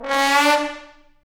Index of /90_sSampleCDs/Roland L-CDX-03 Disk 2/BRS_Bone Sec.FX/BRS_Bone Sec.FX